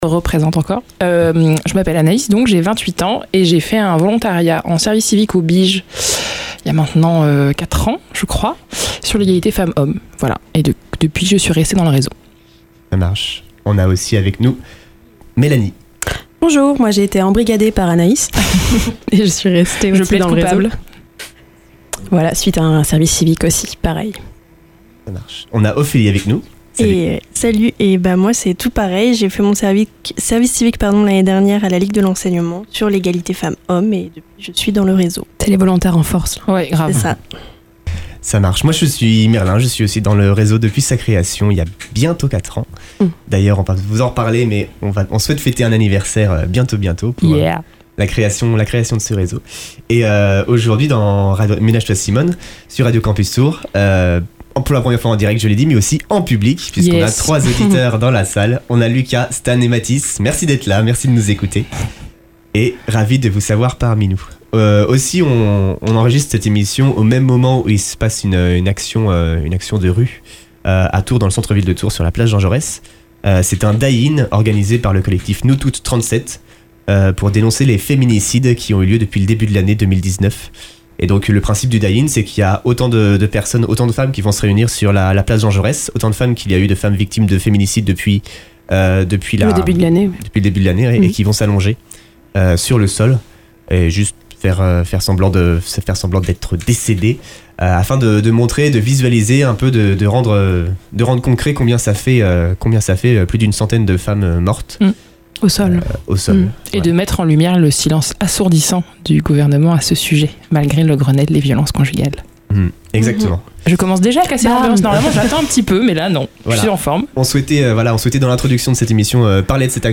Pour la première fois en direct et en public !